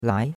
lai2.mp3